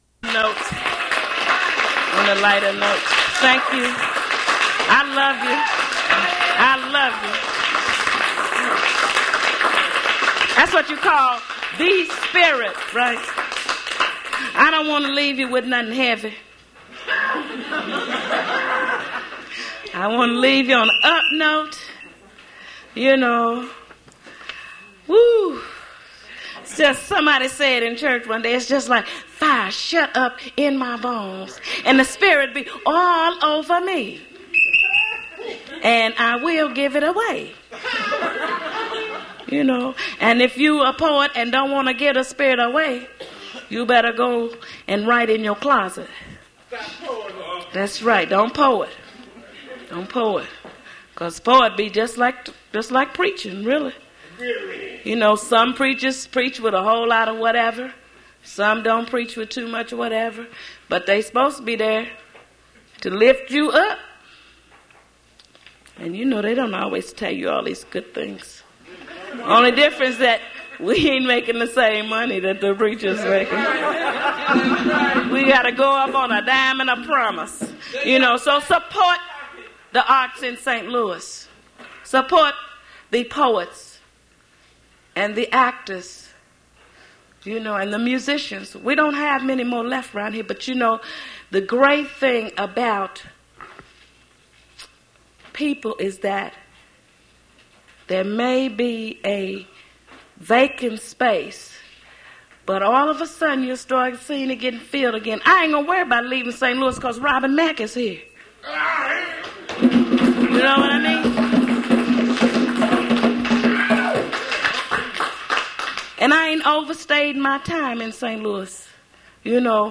Poetry reading
reading her poetry at Duff's Restaurant
mp3 edited access file was created from unedited access file which was sourced from preservation WAV file that was generated from original audio cassette.
Speech-like